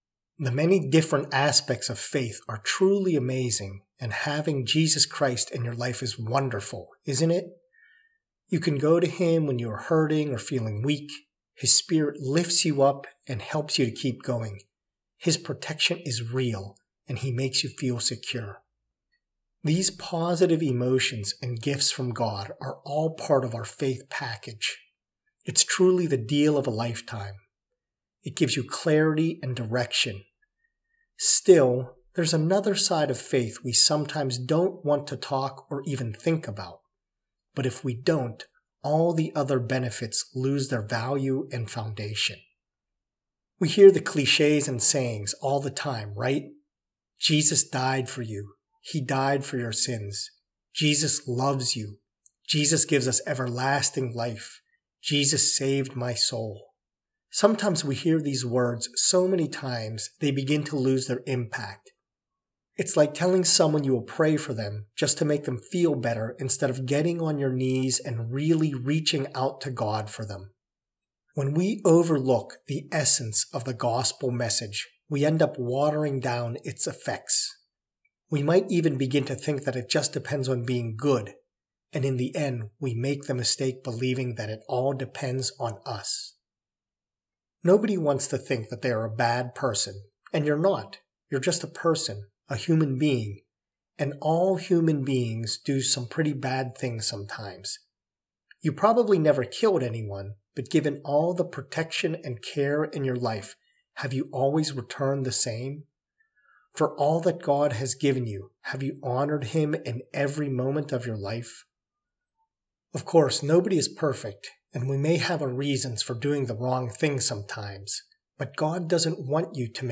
A message and prayer